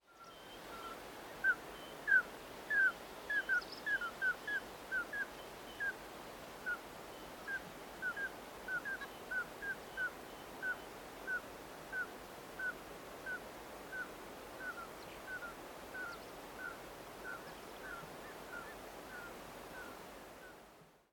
And what do these ducks sound like?
What a beautiful, rather haunting sound….